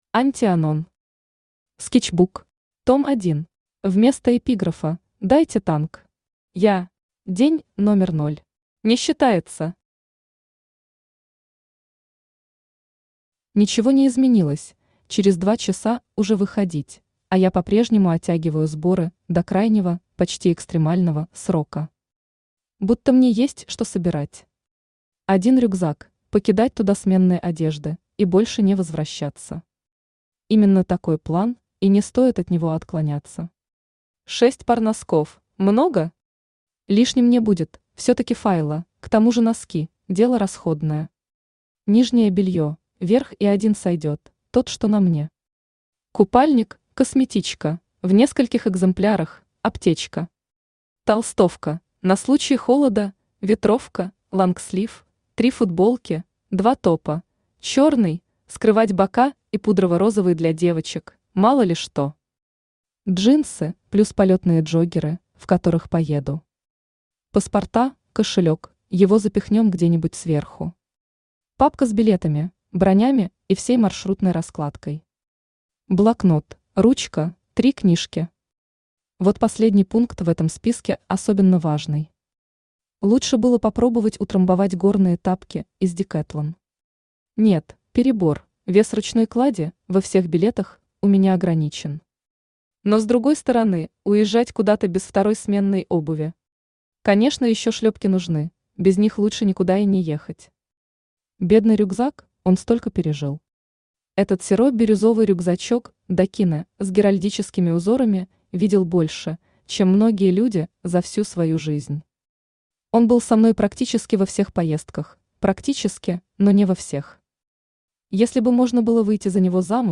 Аудиокнига Скетчбук. Том 1 | Библиотека аудиокниг
Том 1 Автор Анти Анон Читает аудиокнигу Авточтец ЛитРес.